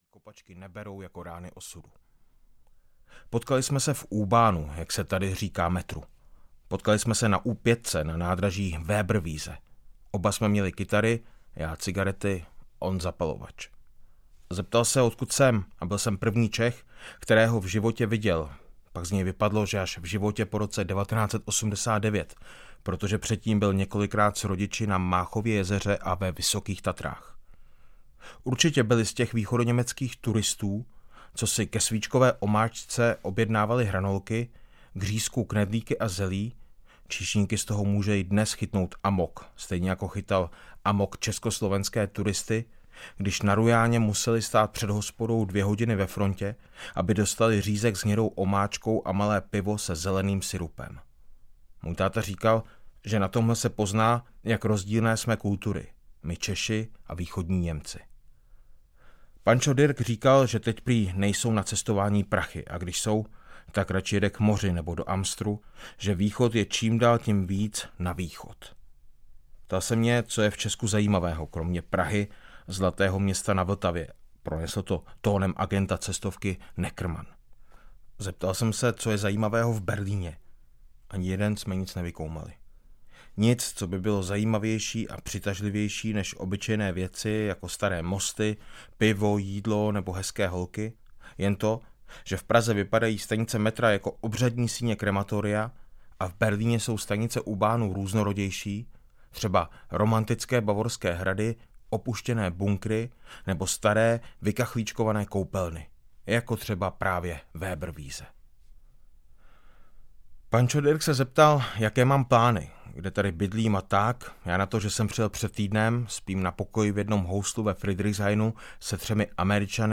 Nebe pod Berlínem - 2. vydání audiokniha
Audiokniha Nebe pod Berlínem, kterou napsal a načetl Jaroslav Rudiš. Román pro živé i mrtvé – rockové příběhy z berlínského metra.
Ukázka z knihy
• InterpretJaroslav Rudiš